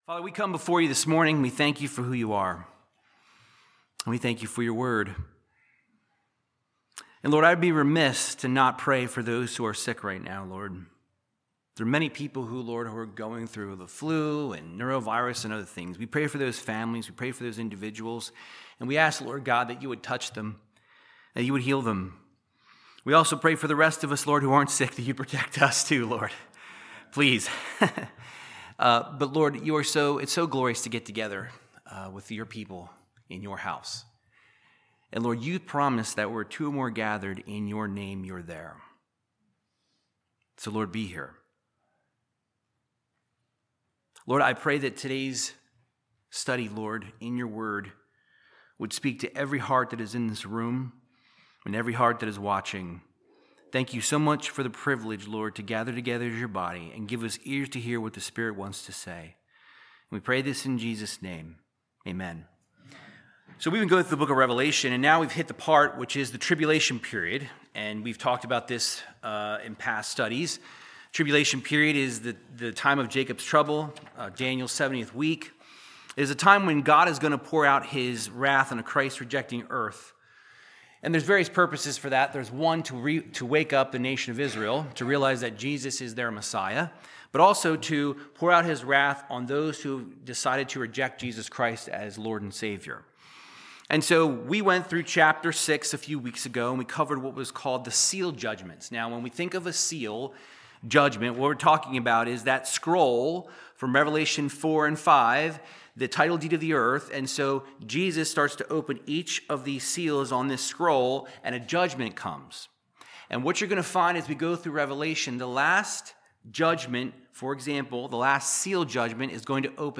Verse by verse Bible teaching through the book of Revelation chapter 8